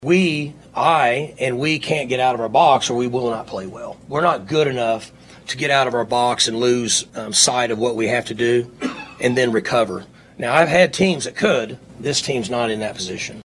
Oklahoma State head football coach Mike Gundy spoke with the media on Monday ahead of the Final Bedlam on the books on Saturday afternoon in Stillwater.